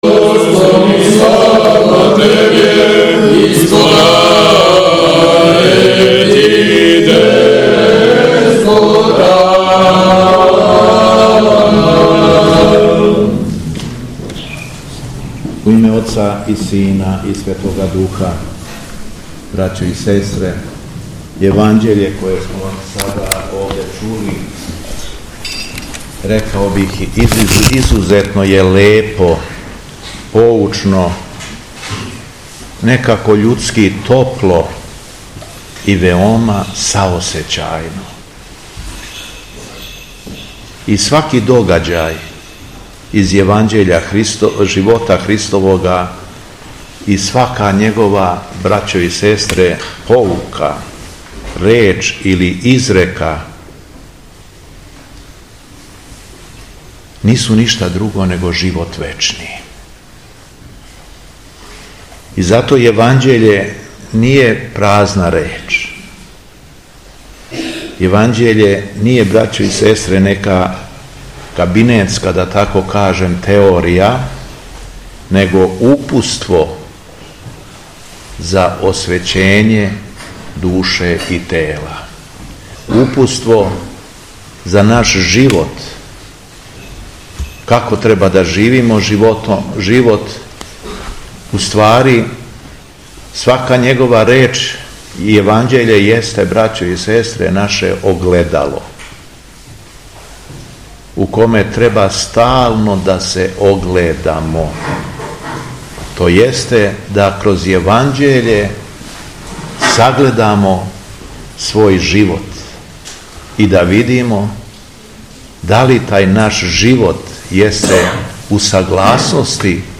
У суботу 23. новембра 2024. године, Његово Високопреосвештенство Митрополит шумадијски Г. Јован служио је Свету Архијерејску Литургији у Цркви Рођења Пресвете Богородице (Карађорђева Црква) у Тополи.
Беседа Његовог Високопреосвештенства Митрополита шумадијског г. Јована
Након прочитаног Јеванђелског зачала верном народу беседио је Владика Јован: